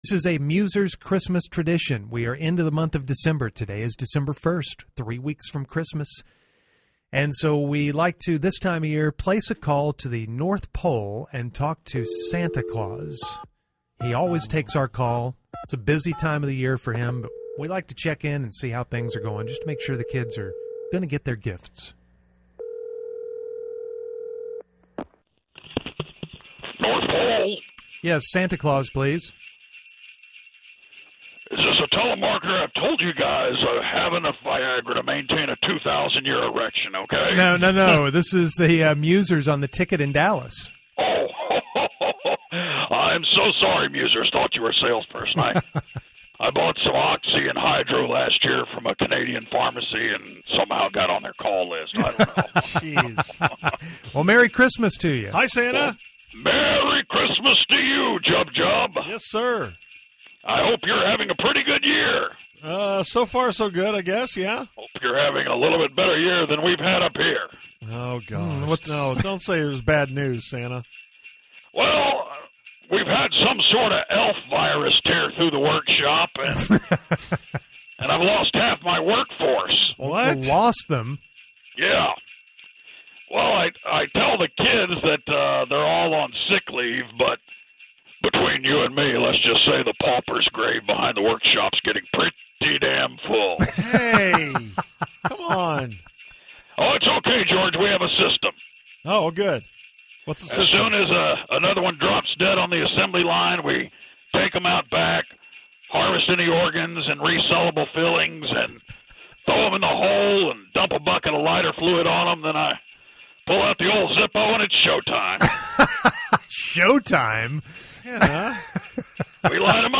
The Musers make their annual phone call to Santa.